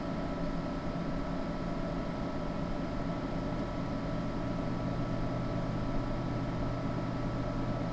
desktop__comp_noise.wav